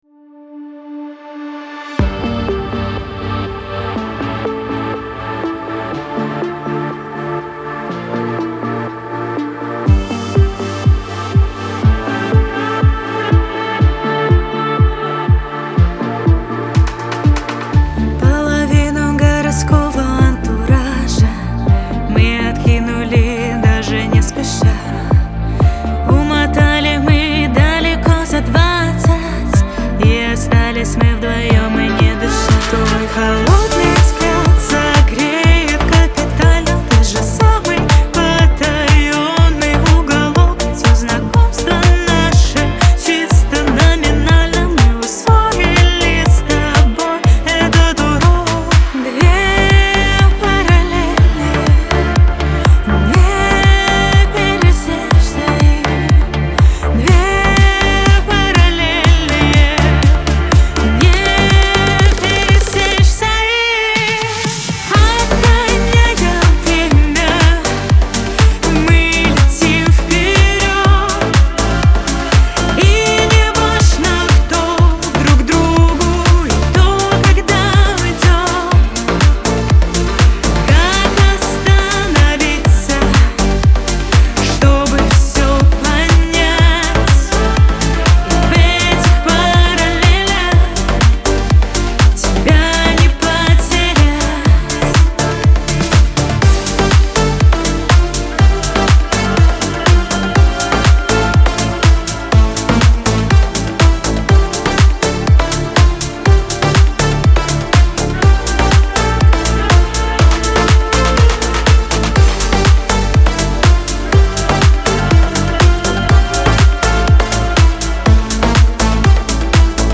Вокал
Меццо-сопрано